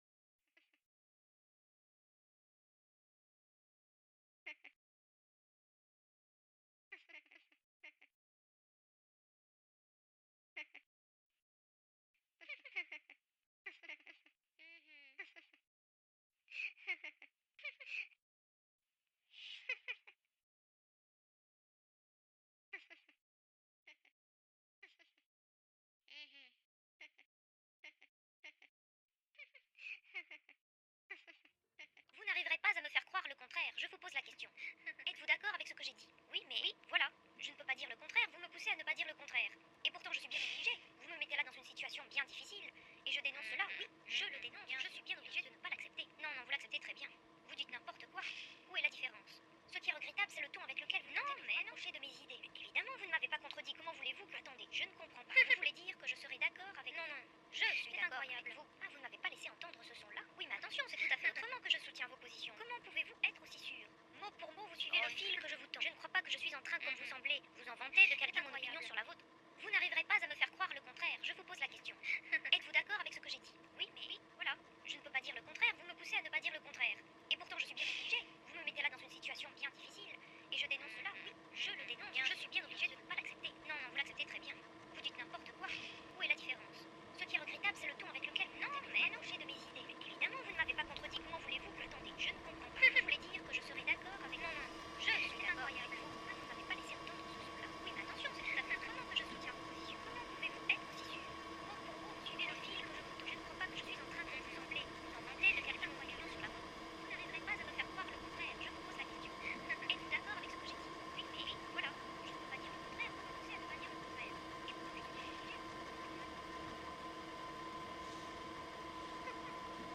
Un débat absurde s’automatise comme une machine qui apparaît grandissante en tant que son du rembobinage stressant d’un magnétoscope. Elle ferme la boucle infernale en rendant le débat définitivement nul. Deux interlocuteurs (ou une même personne qui se répond dans un dialogue intérieur ruminant) sont poussées au désaccord dans leur propre accord, deviennent identiques dans leur répulsivose ou réflexe d’opposition.